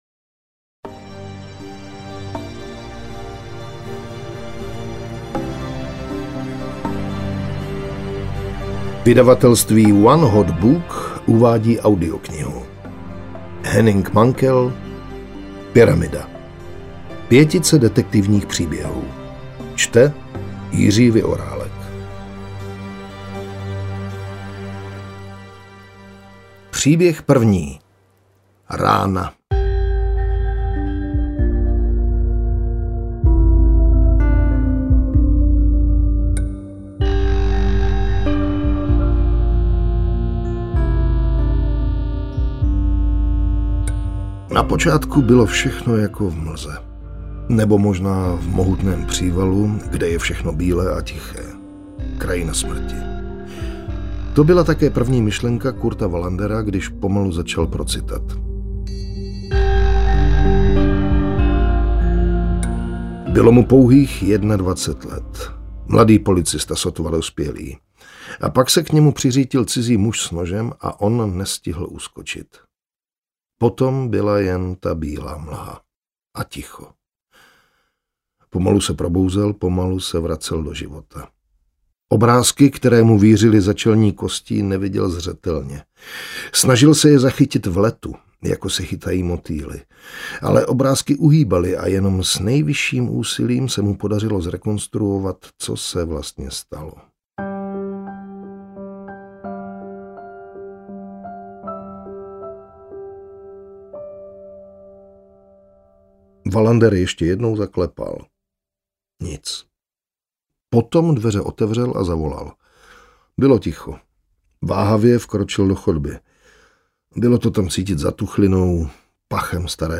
Pyramida audiokniha
Ukázka z knihy